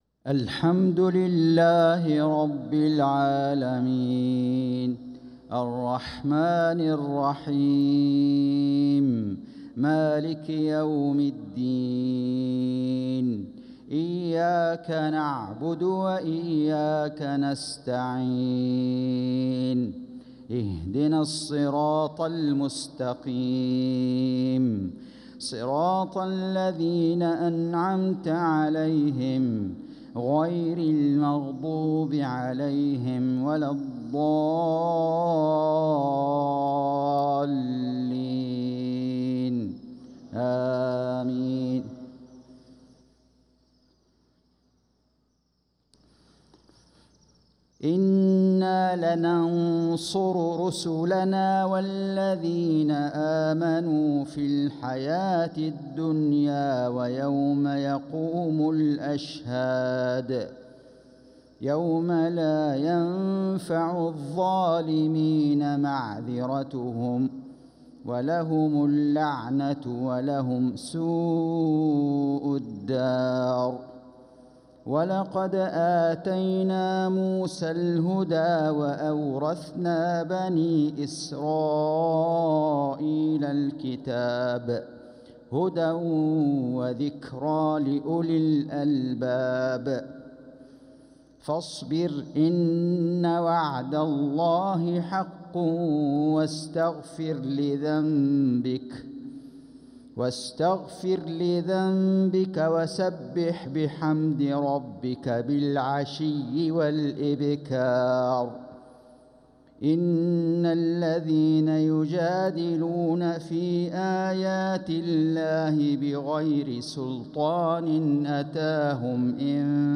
صلاة المغرب للقارئ فيصل غزاوي 11 ربيع الآخر 1446 هـ
تِلَاوَات الْحَرَمَيْن .